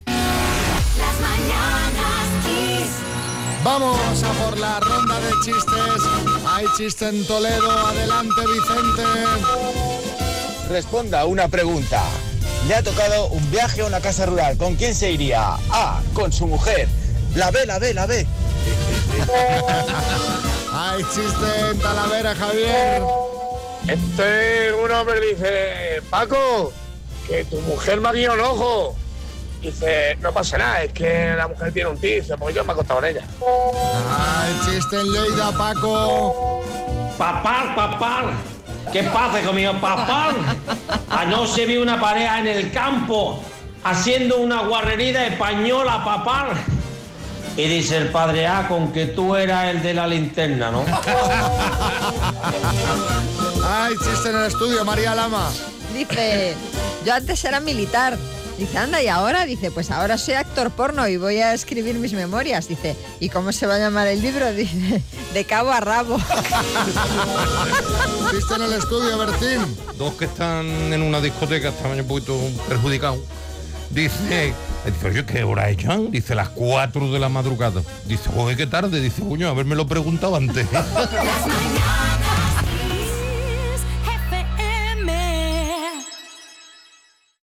RONDA-DE-CHISTES-1706-1.mp3